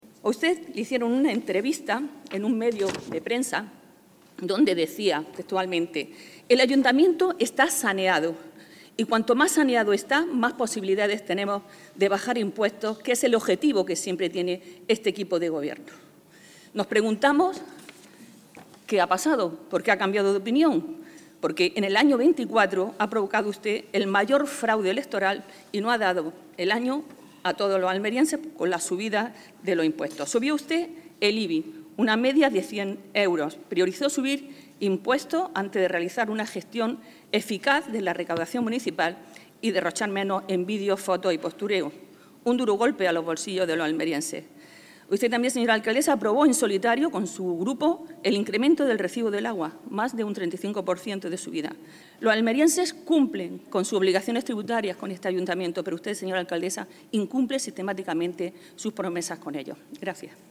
Si el Ayuntamiento está saneado, tal y como dice el PP, ¿por qué aumentan la presión fiscal sobre los almerienses?”, ha espetado Valverde a la alcaldesa durante su intervención en el Pleno, al tiempo que le ha recordado que “ha cometido usted un gran fraude electoral porque antes de las elecciones prometió no subir los impuestos”.